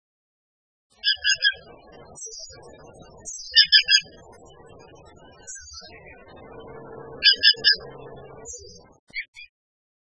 2125e「鳥の鳴声」
〔ツグミ〕クィークィー／クワックワッ／木の実やミミズなどを食べる，普通・冬鳥，